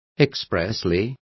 Complete with pronunciation of the translation of expressly.